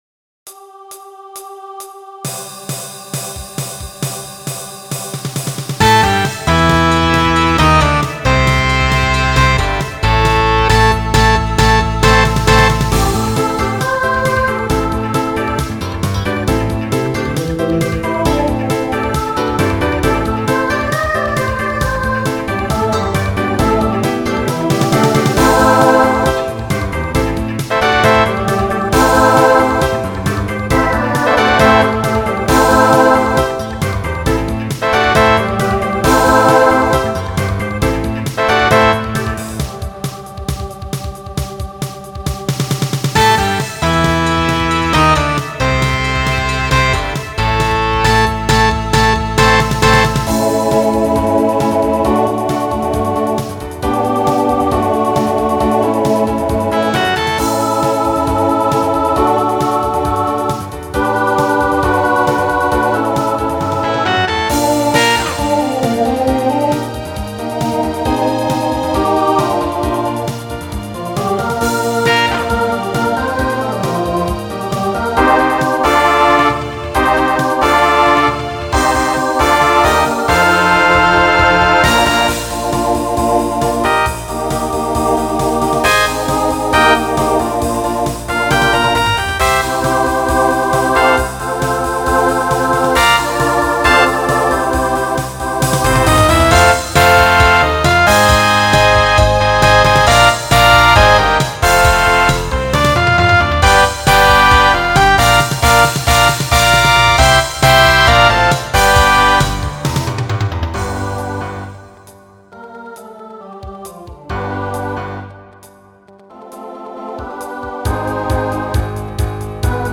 Voicing SATB Instrumental combo Genre Rock Decade 1960s